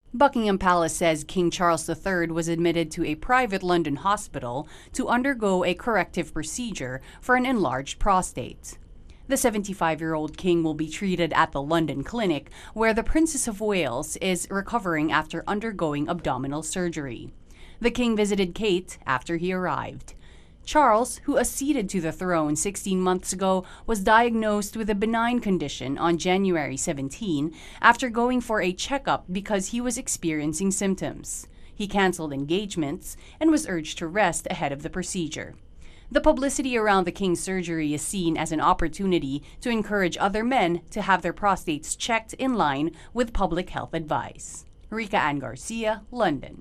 britain-king-charles-iiioperation-intro-voicer-apa355966.mp3